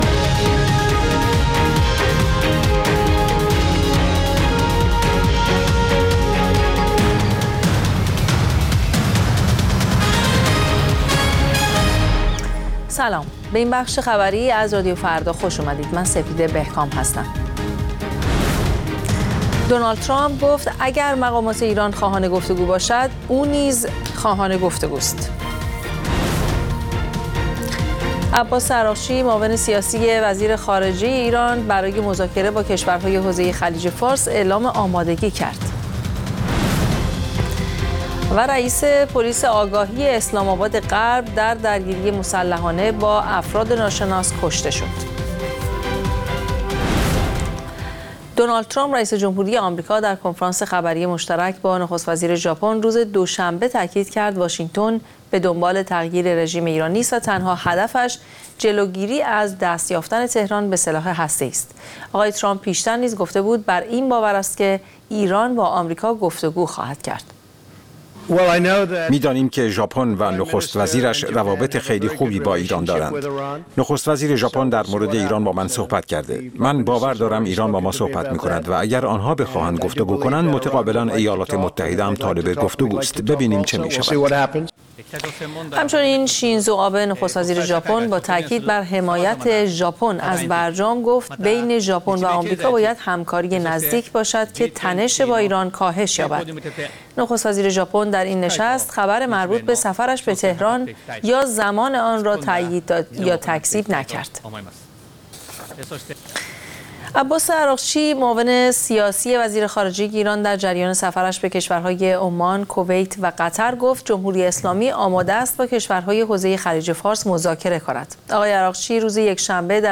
اخبار رادیو فردا، ساعت ۱۲:۰۰